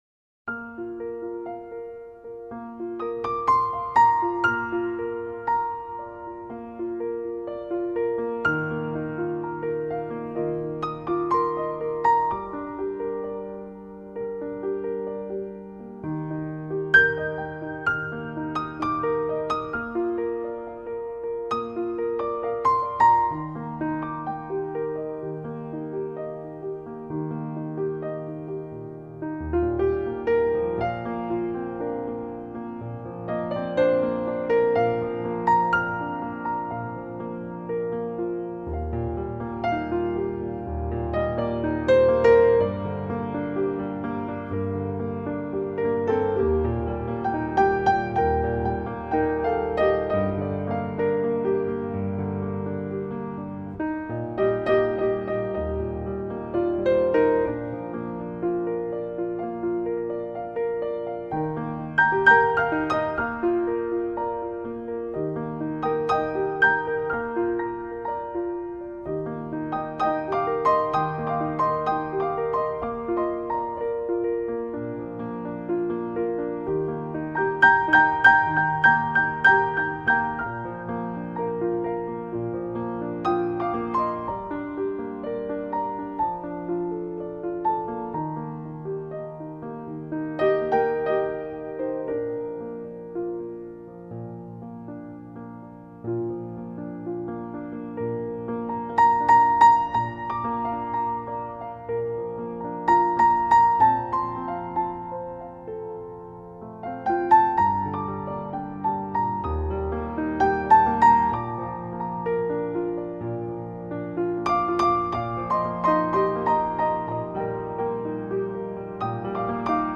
[2005-7-8]钢琴欣赏——雨后